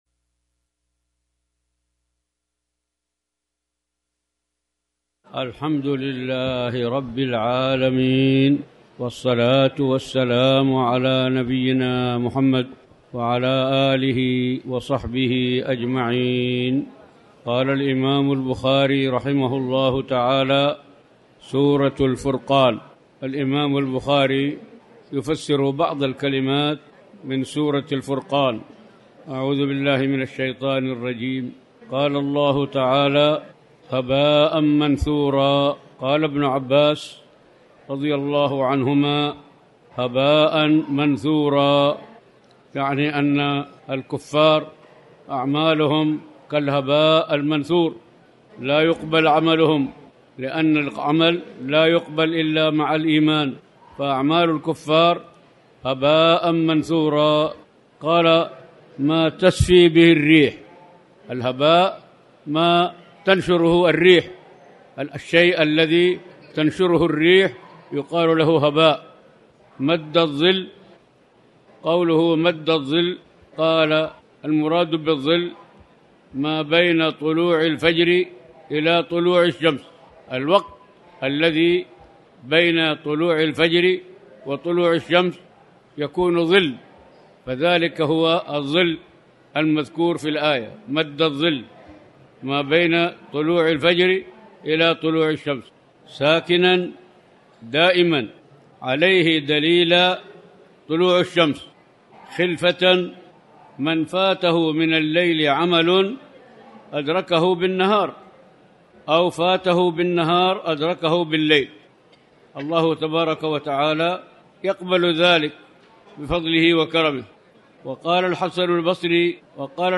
تاريخ النشر ٢٧ ربيع الأول ١٤٤٠ هـ المكان: المسجد الحرام الشيخ